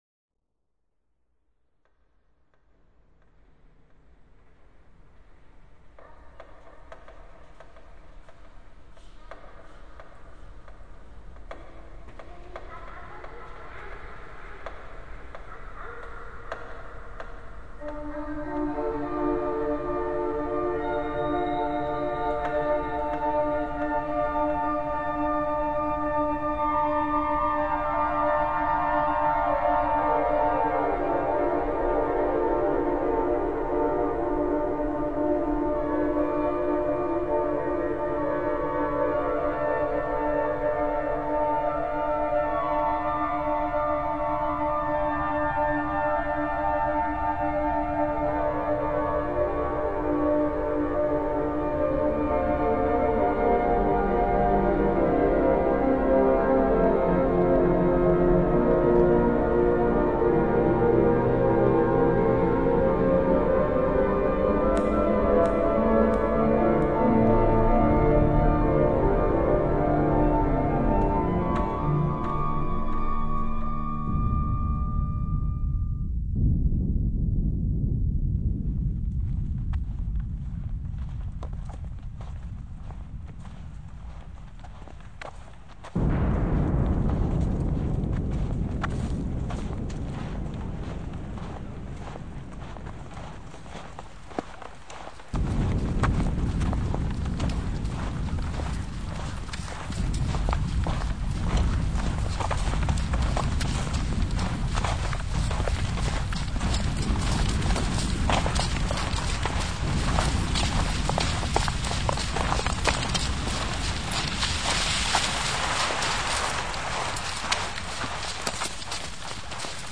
7 soundscape-compositions of environmental sounds